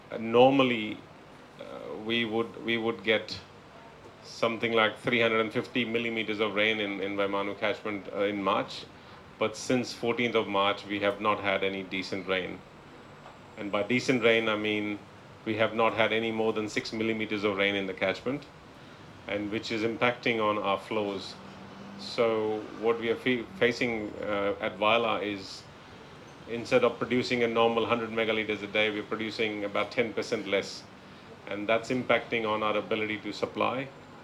Speaking during the Tailevu Provincial Council meeting in Nausori yesterday